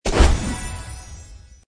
胜利音效_01.mp3